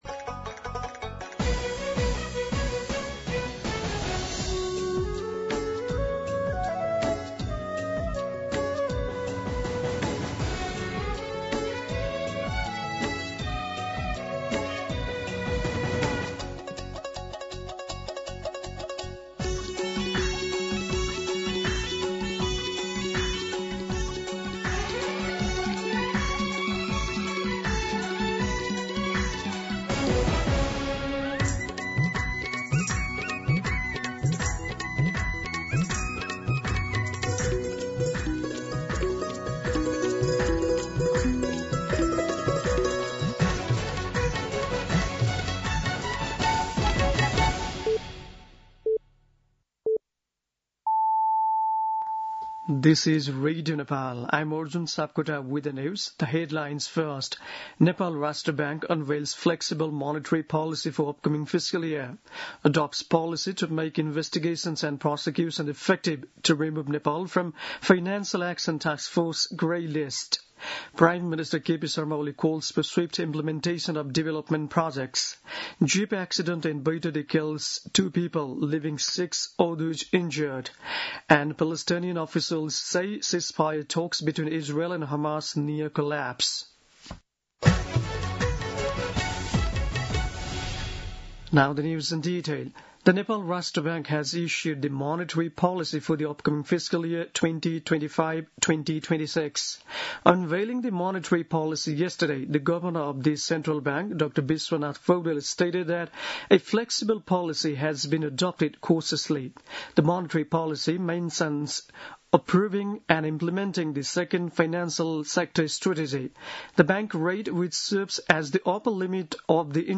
दिउँसो २ बजेको अङ्ग्रेजी समाचार : २८ असार , २०८२
2-pm-English-News.mp3